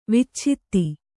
♪ vicchitti